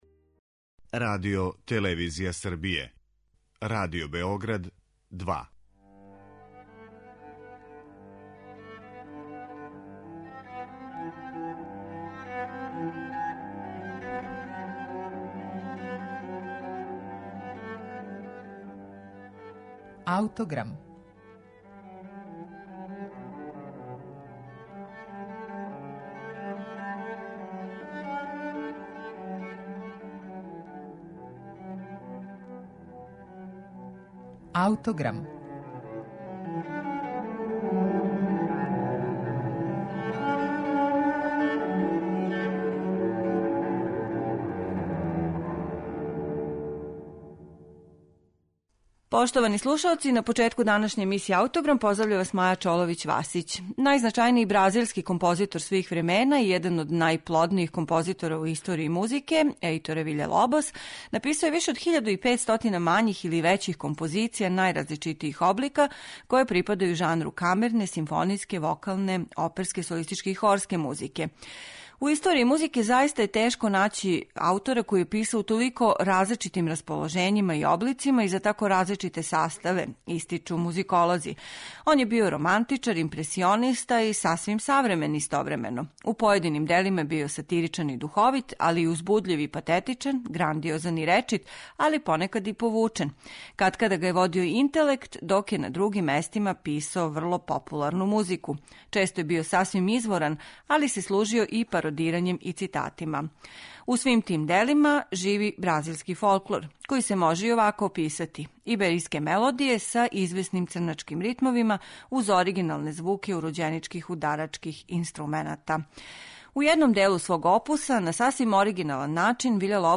Документарни програм
Чућете репортажу Свет стварности и уметнички идеали, у којој ће три млада уметника - сликар, композитор и песник, говорити о позицији ствараоца у данашњој друштвеној стварности. Они ће, свако из свог угла, представити шта за њих значи живети од уметности и на који начин успевају или настоје да ускладе своје стваралачке пориве и практичне захтеве реалности.